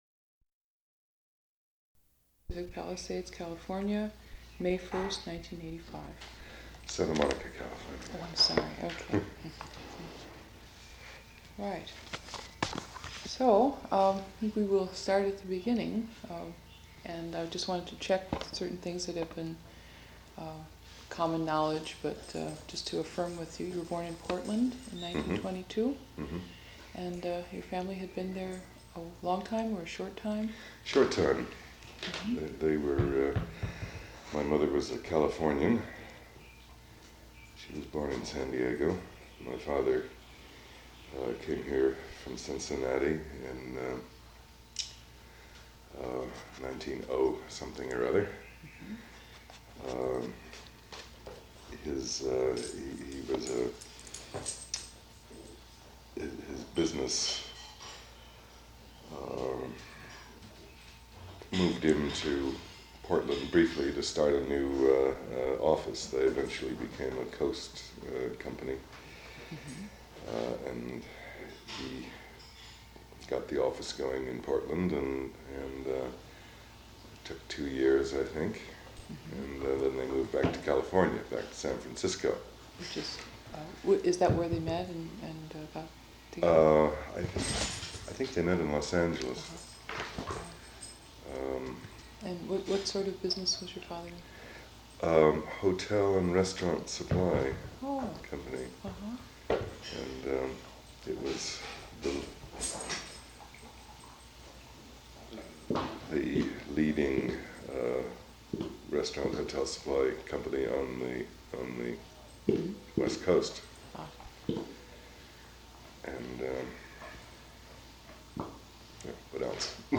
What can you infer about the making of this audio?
Size: Sound recordings: 10 sound cassettes.